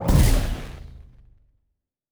Sci Fi Explosion 12.wav